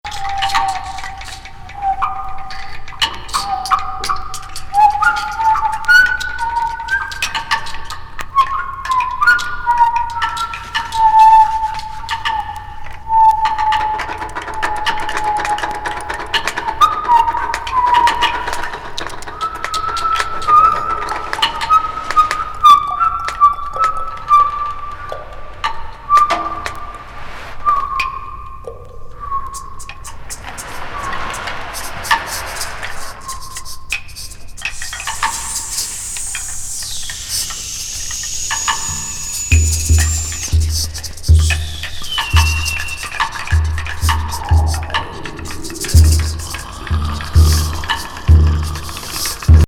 UKフリーインプロ～ミニマル。